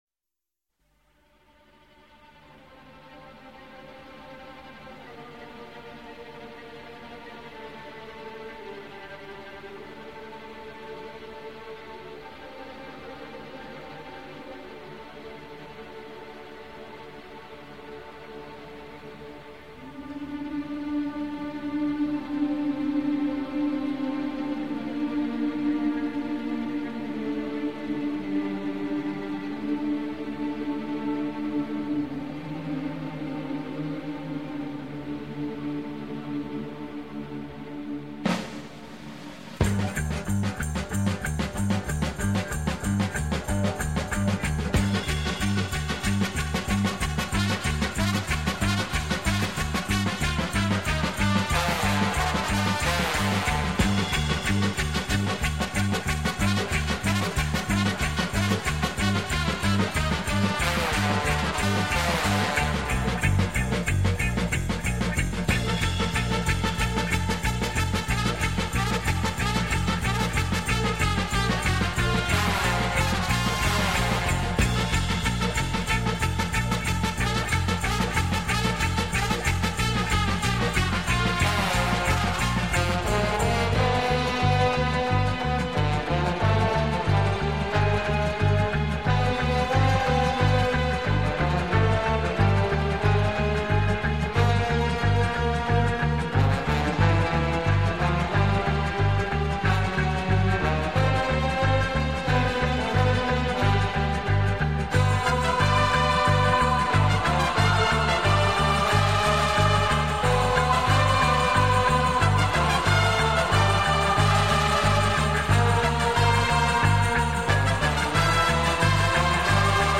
Понравилось, хотя и беднова-то инструментов